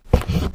MISC Wood, Foot Scrape 01.wav